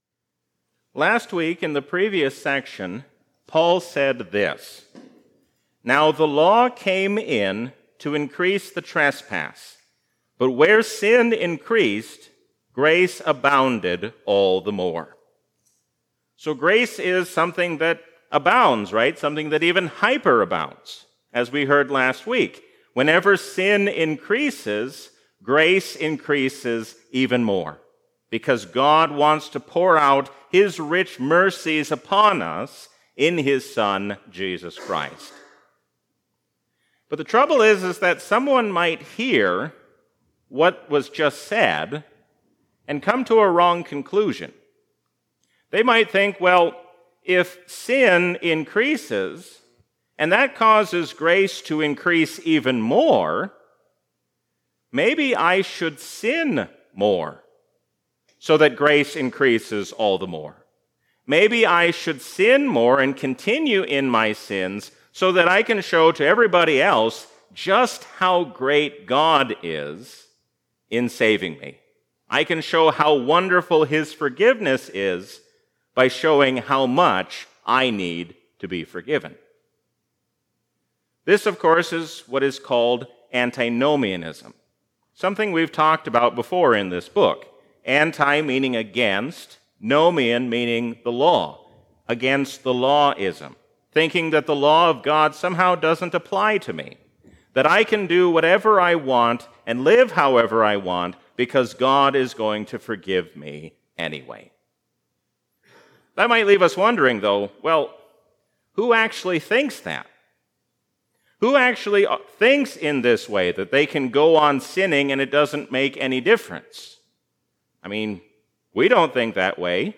A sermon from the season "Trinity 2022." When we humble ourselves under the hand of God, then we have no reason to be anxious about the future.